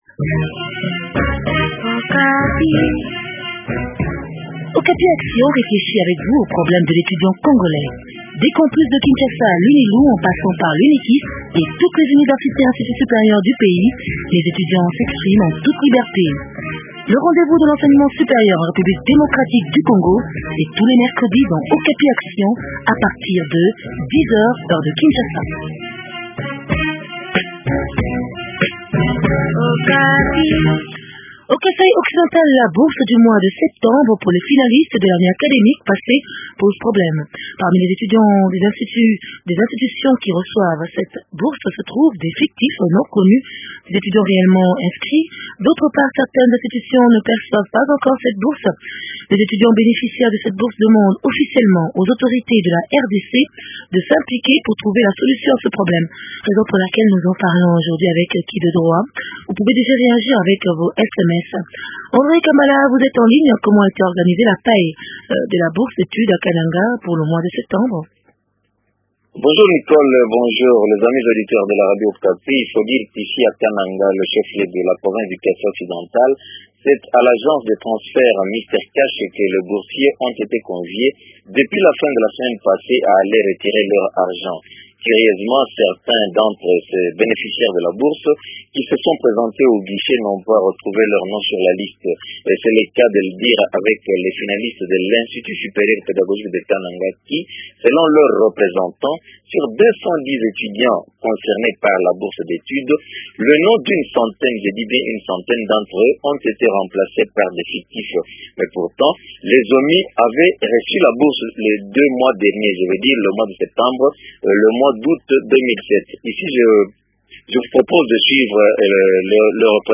Alidor Mbengele, Ministre provincial de l’Education répond aux questions